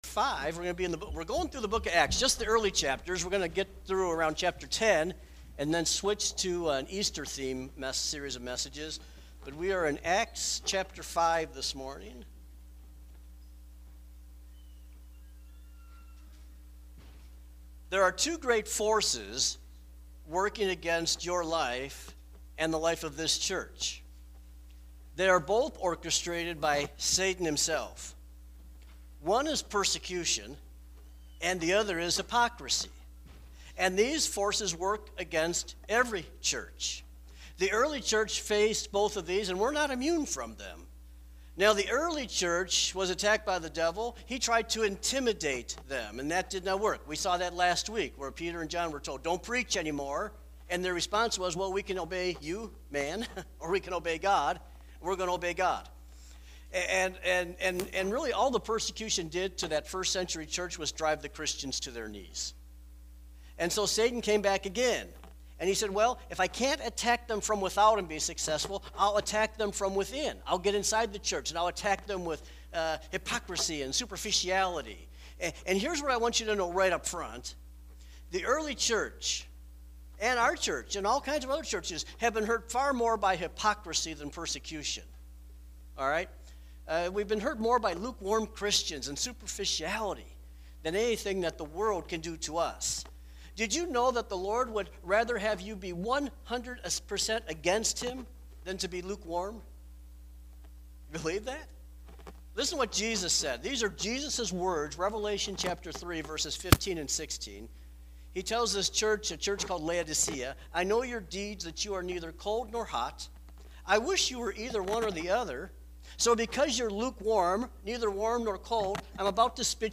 Sermons | Tri County Christian Church